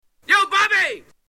Tags: Comedian Actor John Candy John Candy clips Prank Call